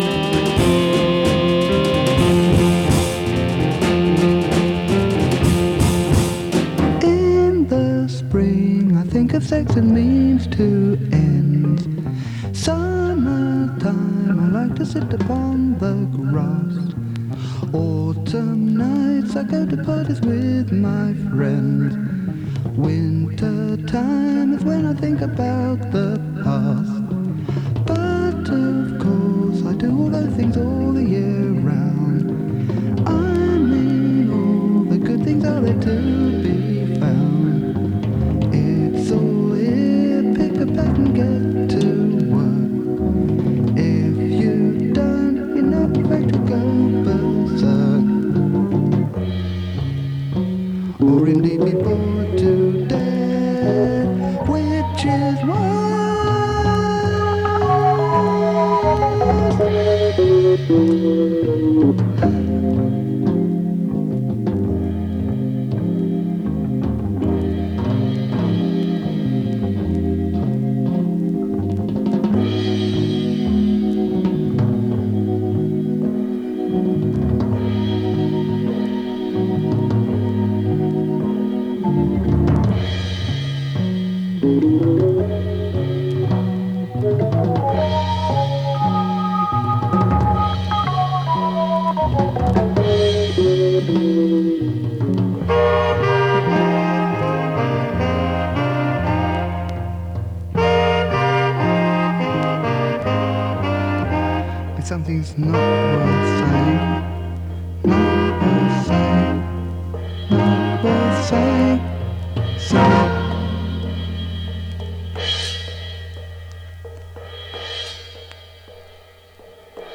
音の薄い部分で軽いサー・ノイズ。時折軽いパチ・ノイズ。